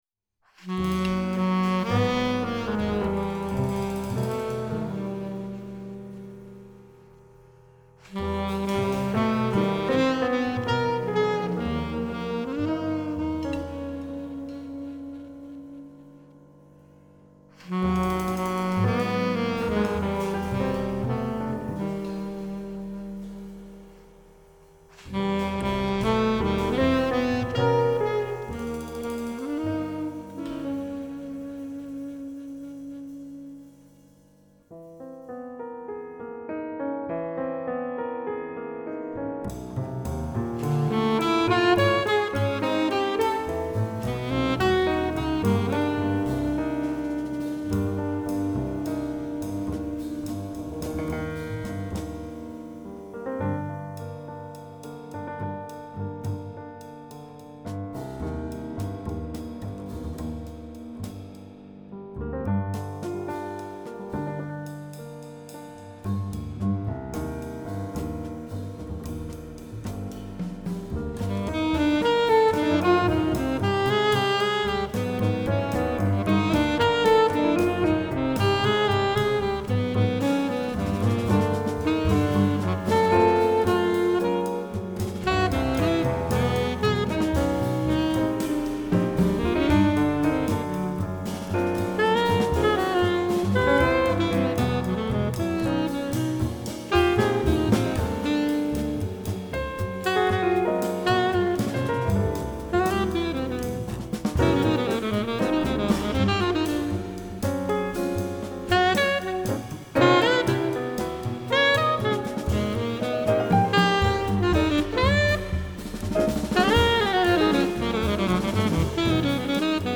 Genre : Jazz